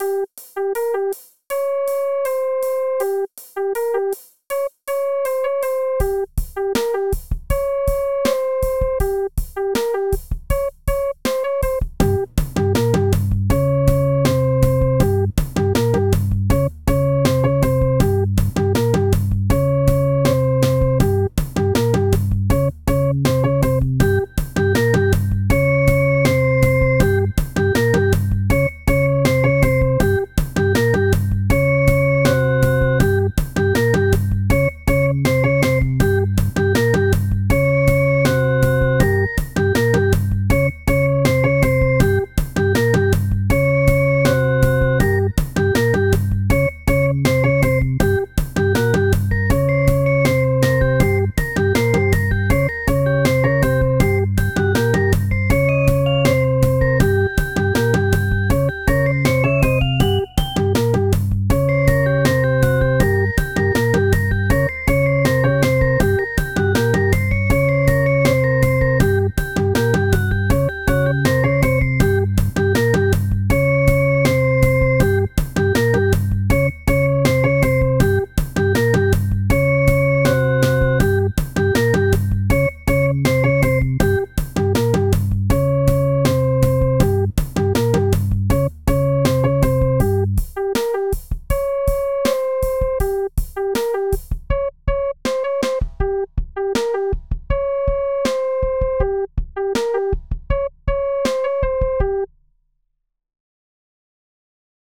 Pieza de Electro Jazz
Música electrónica
jazz
melodía
sintetizador